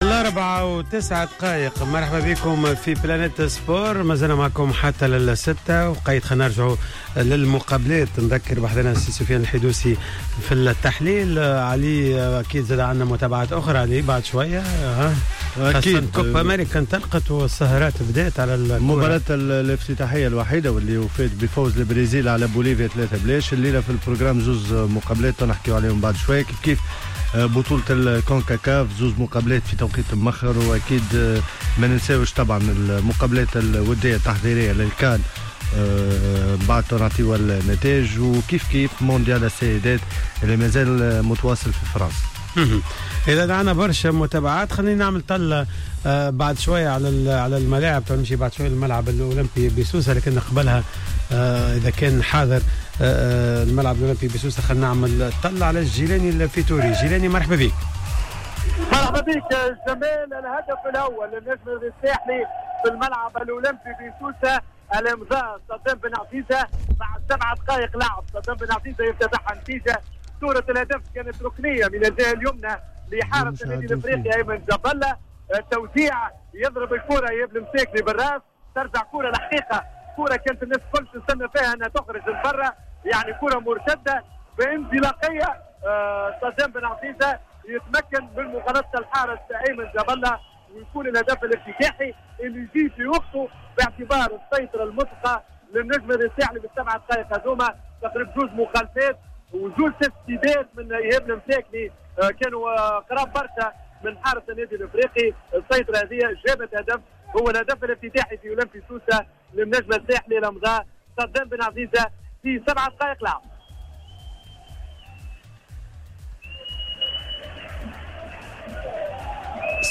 و كان في الحضور عدد من الإعلاميين